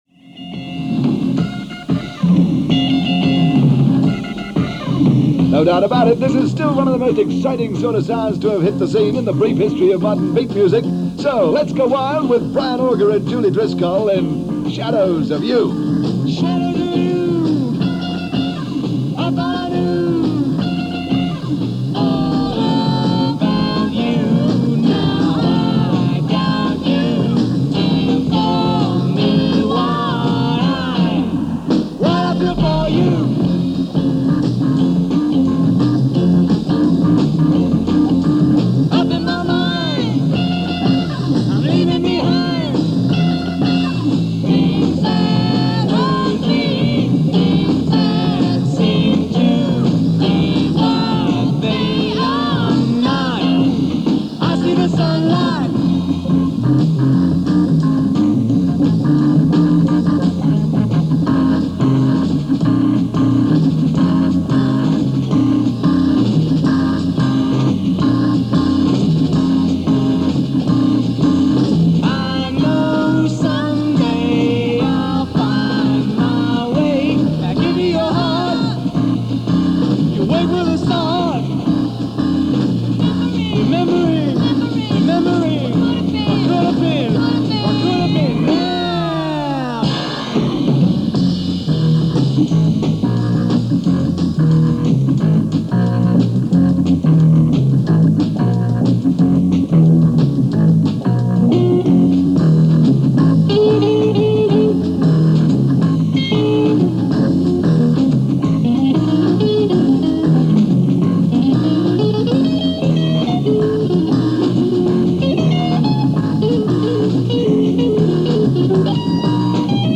Prog-Rock
keyboard virtuoso
vocalist